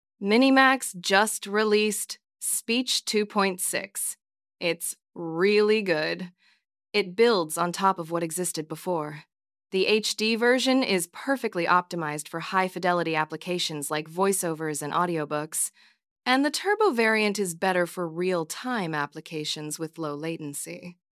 Low‑latency MiniMax Speech 2.6 Turbo brings multilingual, emotional text-to-speech to Replicate with 300+ voices and real-time friendly pricing
"channel": "mono",
"voice_id": "Wise_Woman",
Generating speech with model speech-2.6-turbo